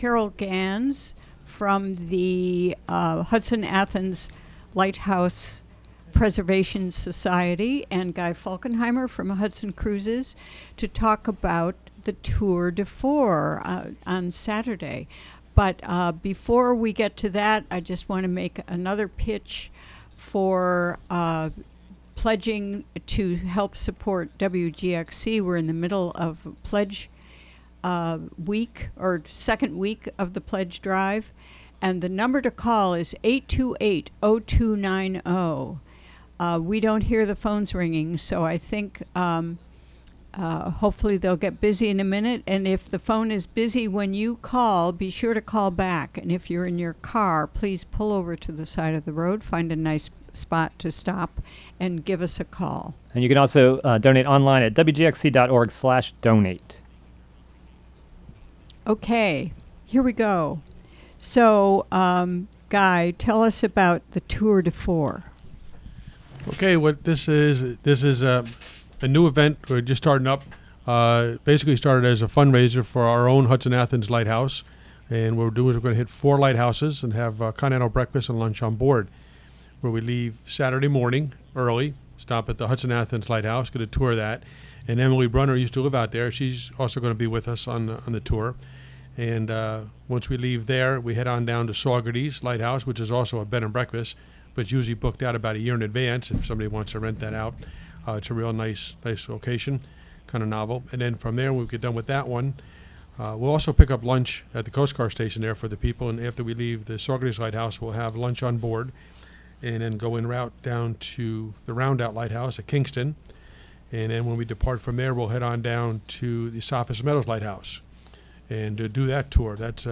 Interview
From "WGXC Afternoon Show," at Hudson studio.